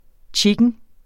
Udtale [ ˈtjigən ]